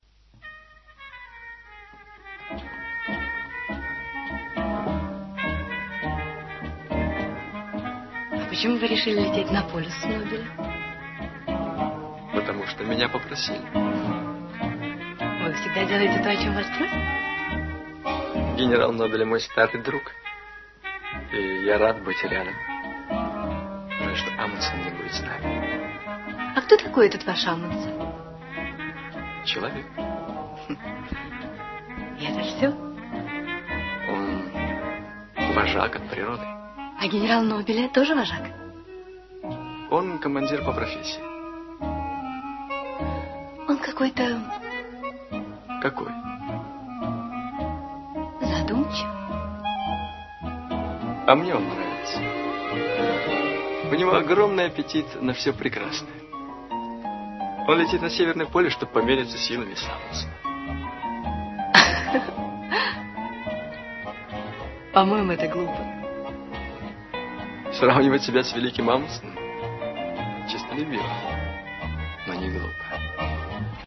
Уютная обстановка… негромкая приятная музыка…